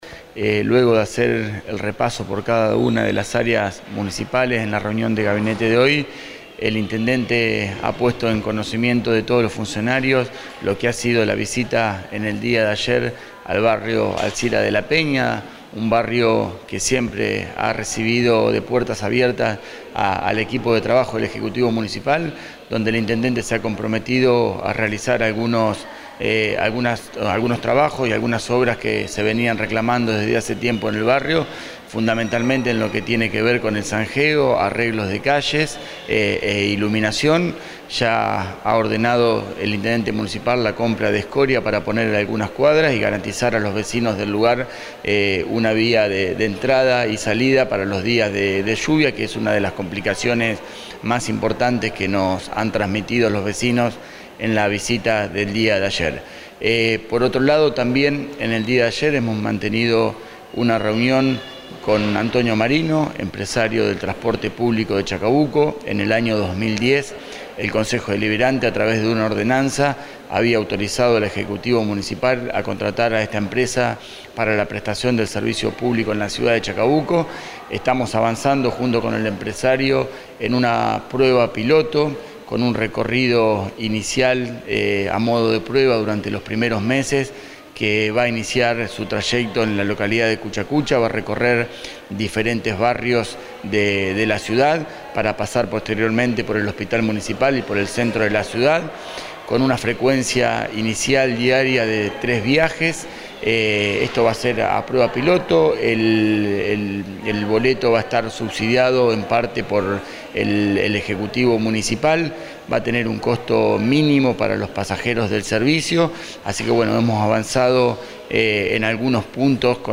MARIANO CÁMERA EN CONFERENCIA DE PRENSA
Adjuntamos audio de la conferencia de prensa.
MARIANO-CAMERA-SEC.-DE-GOBIERNO-REUNION-DE-GABINTE-DIA-MIERCOLES-24..mp3